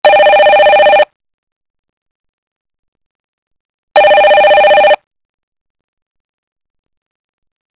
call_ringtone1.wav